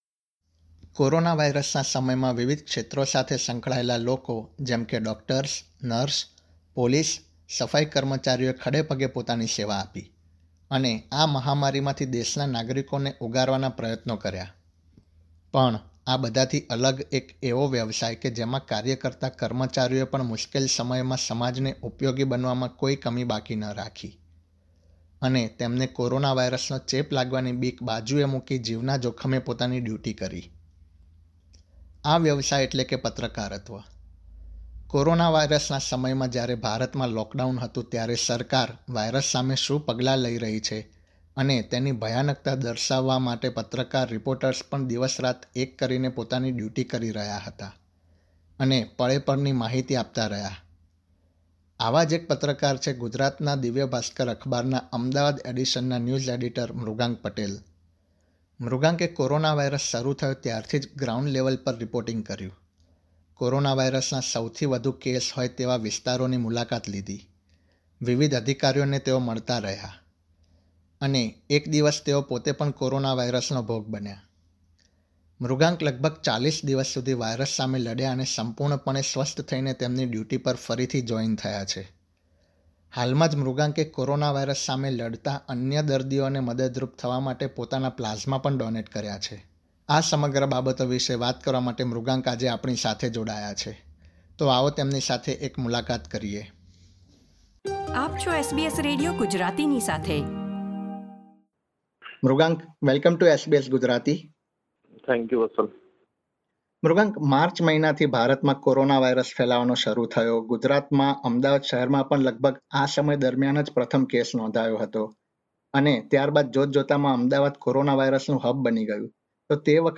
From testing positive to coronavirus to donating plasma, this Indian journalist shares his story